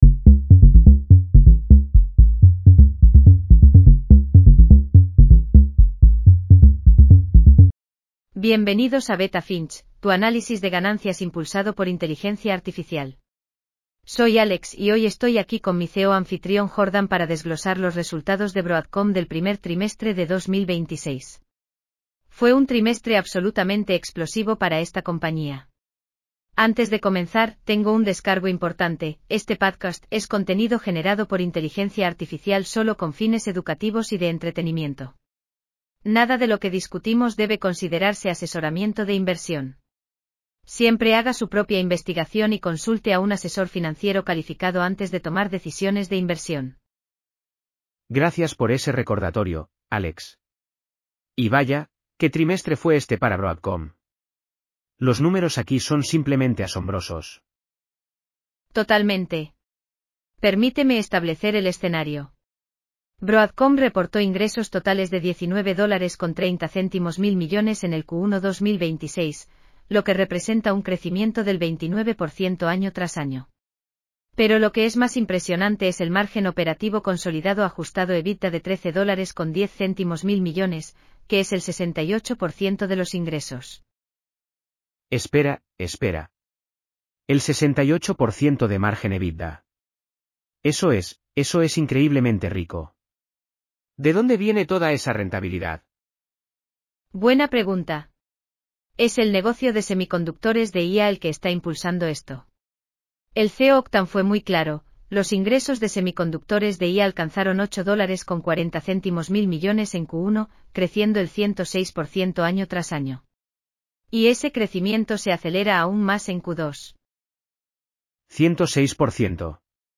AI-powered earnings call analysis for Broadcom (AVGO) Q1 2026 in Español.